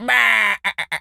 sheep_hurt_death_02.wav